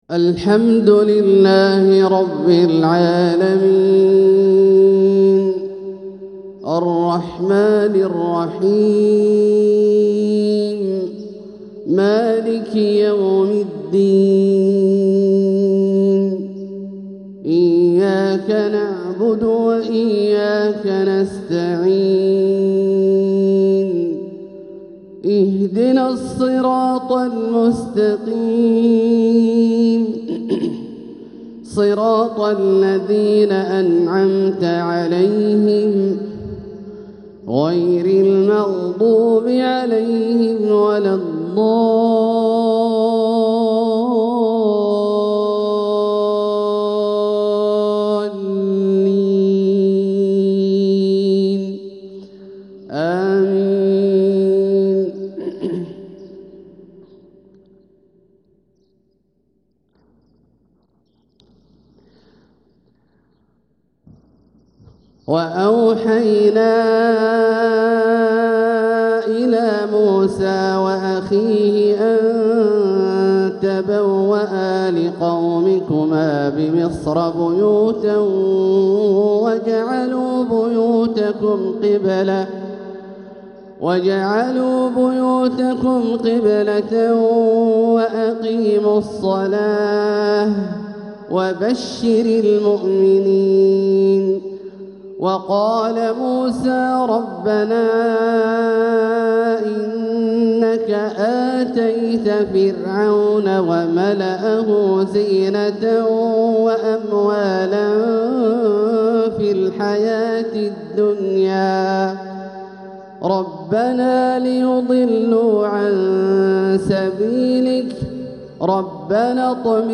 تلاوة ذات خشوع فائق وترنم رائق يستلذ به السمع ويتحدر منه الدمع من سورة يونس | فجر 4-5-1446هـ > ١٤٤٦ هـ > الفروض - تلاوات عبدالله الجهني